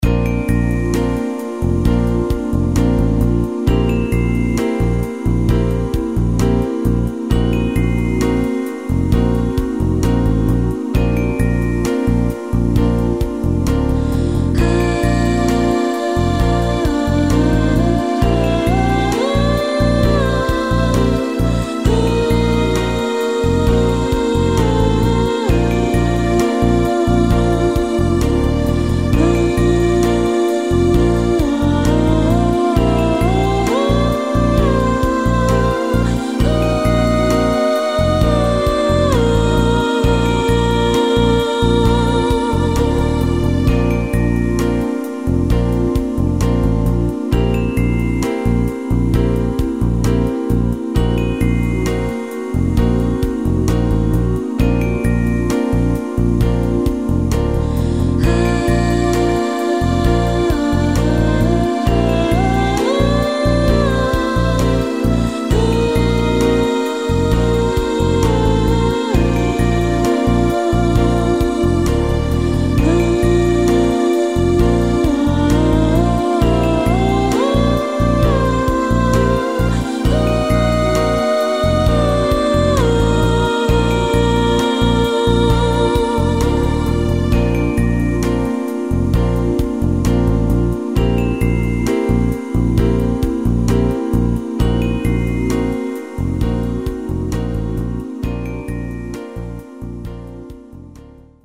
珍しくスキャット曲。
・使用音源：YAMAHA motif-RACK